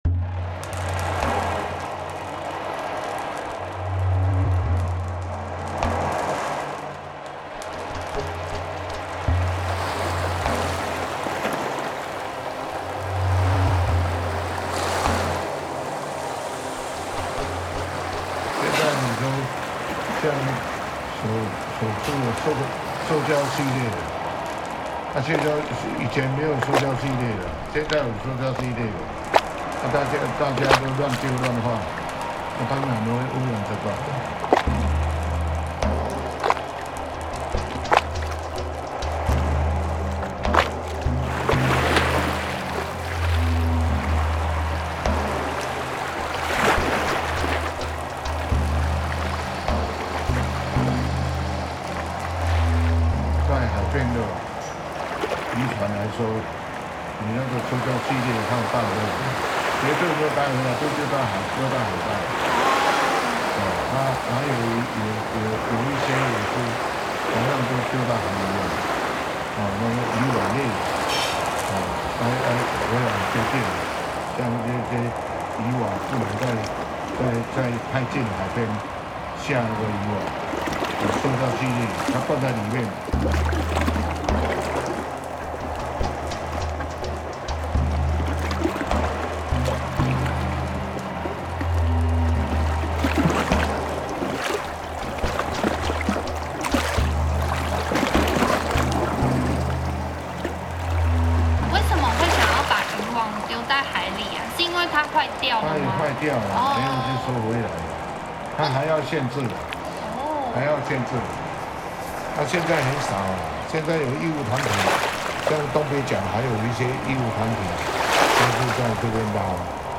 These tracks feature environmental recordings, oral histories, and storytelling, echoing the voices, rhythms, and transformations of coastal life in Jinshan.
浪潮之警 Warning Waves: Testimonies on marine pollution, climate change, and ecological precarity echo with a sense of urgency and reverence for what has been lost or is still at risk.